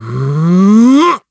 Siegfried-Vox_Casting2_kr.wav